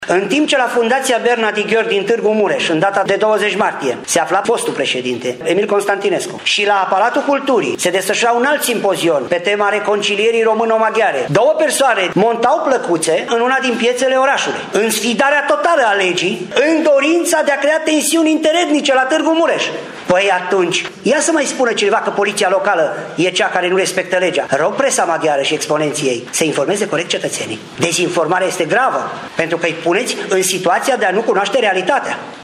Declarația aparține șefului Poliției Locale, Valentin Bretfelean, care a susținut astăzi o conferință de presă pe acest subiect, mult discutat în ultimul timp la Tîrgu-Mureș.